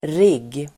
Uttal: [rig:]